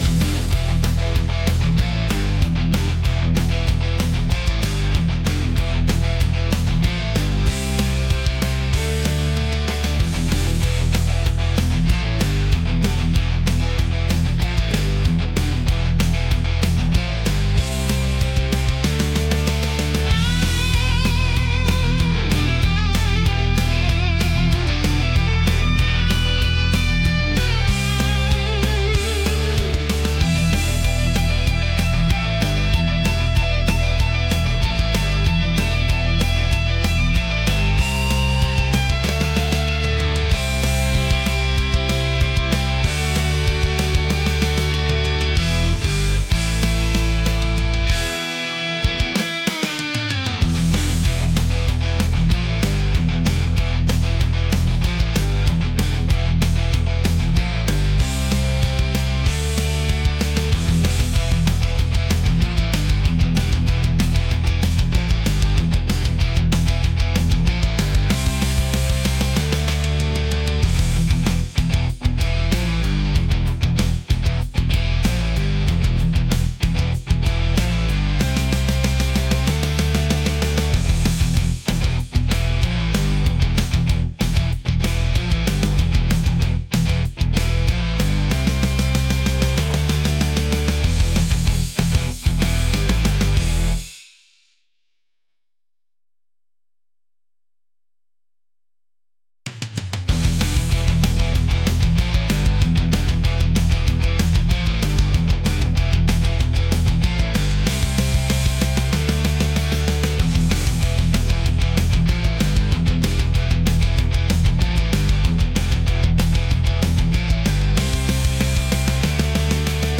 intense | rock | aggressive